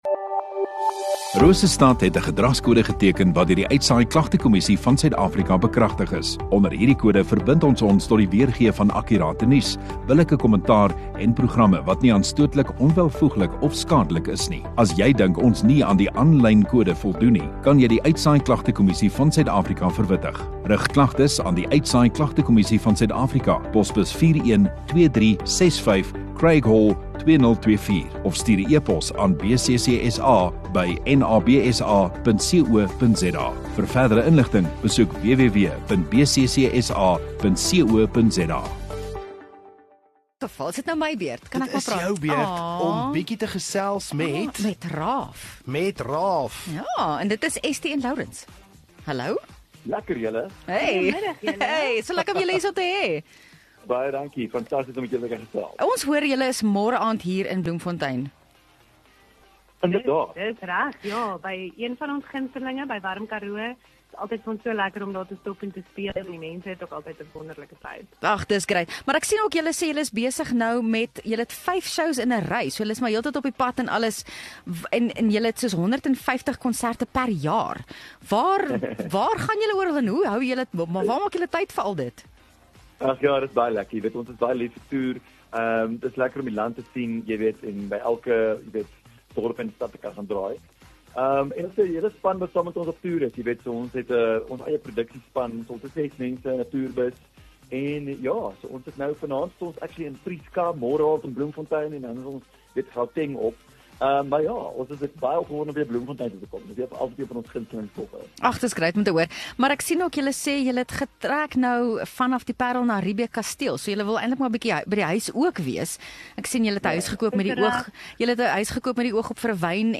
Kunstenaar Onderhoude